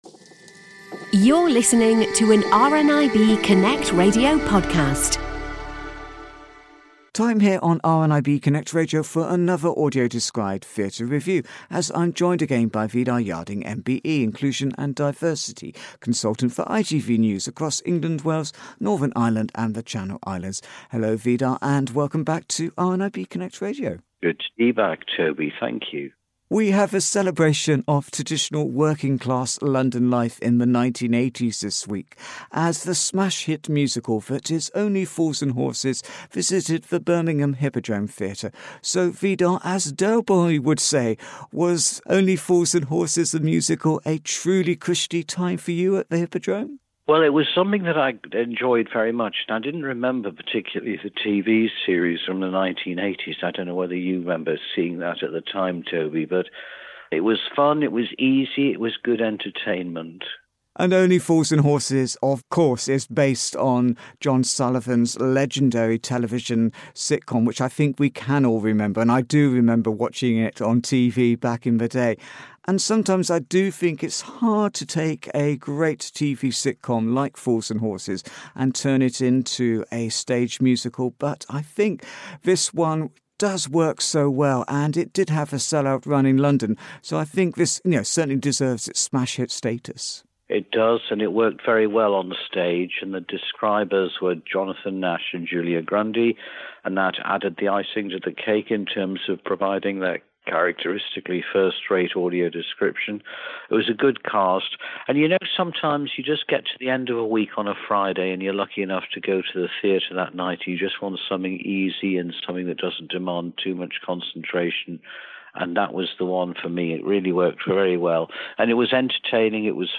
Only Fools and Horses The Musical, AD Theatre Review